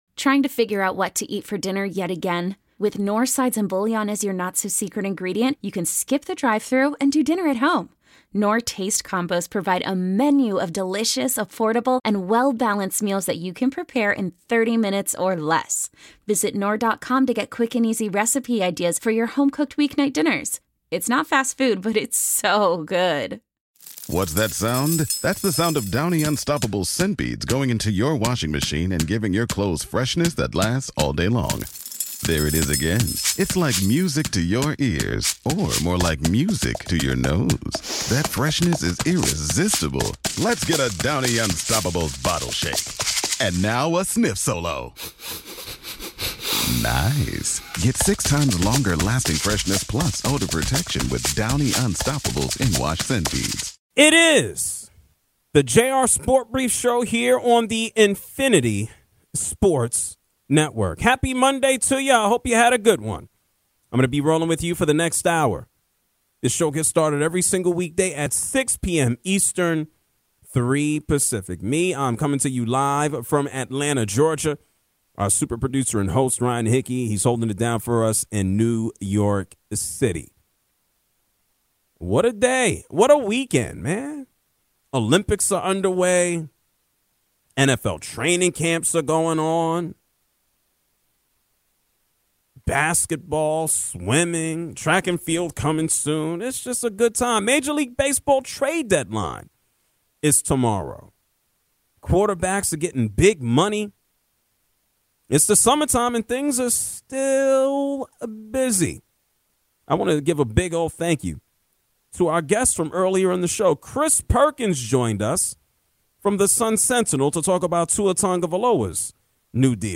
Is there one current athlete that is beloved? l Callers on the most lovable athletes l This Day In Sports History